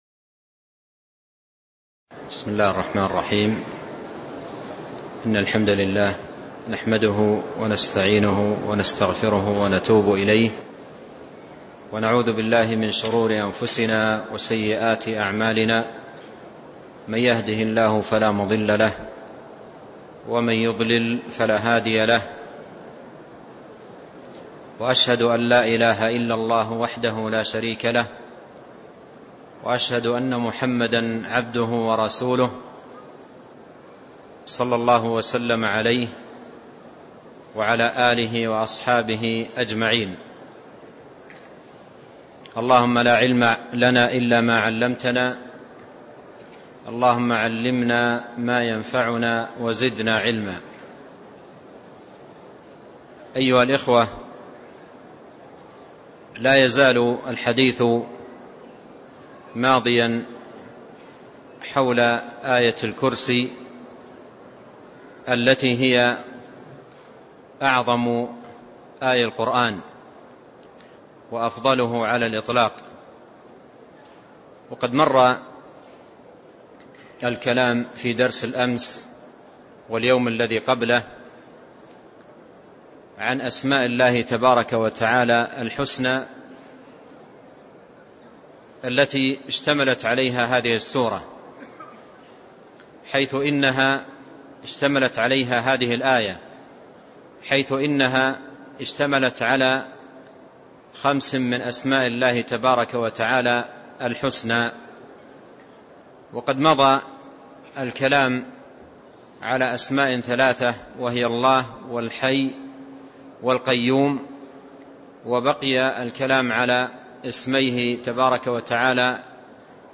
تاريخ النشر ١١ جمادى الآخرة ١٤٢٧ هـ المكان: المسجد النبوي الشيخ